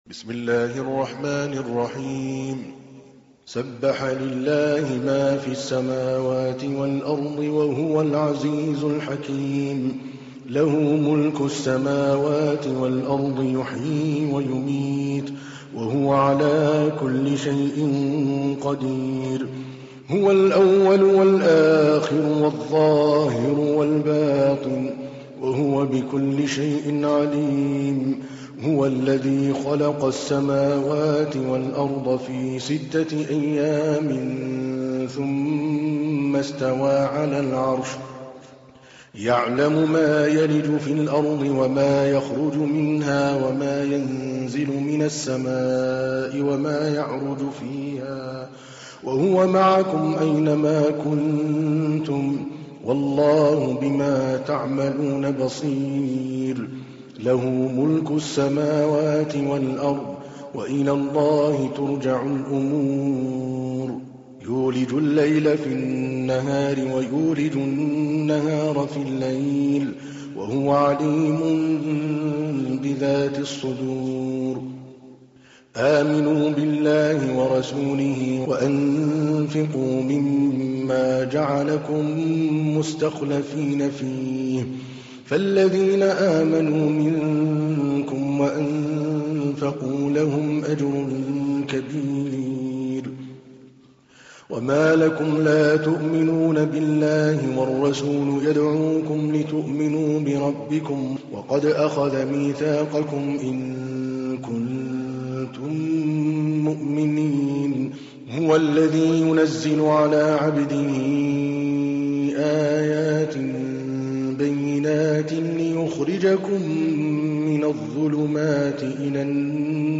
تحميل : 57. سورة الحديد / القارئ عادل الكلباني / القرآن الكريم / موقع يا حسين